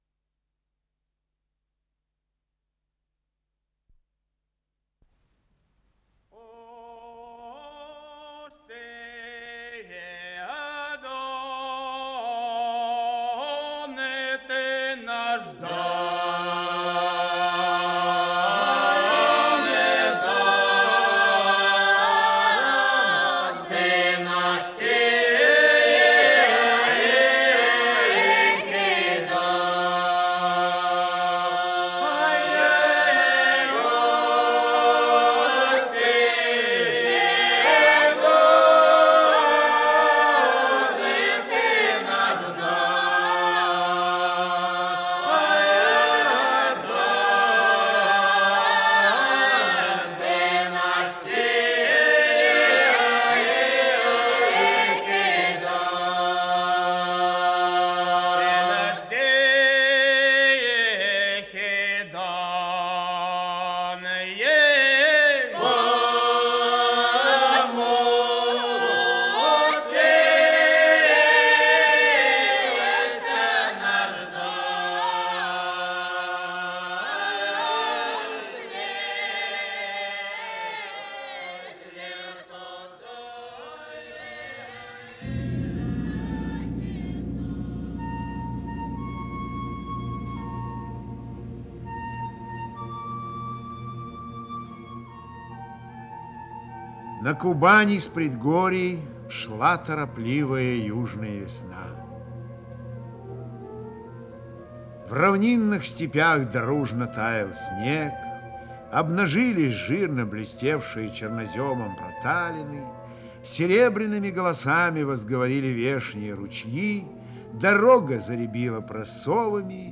Исполнитель: Михаил Ульянов Фольклорный ансамбль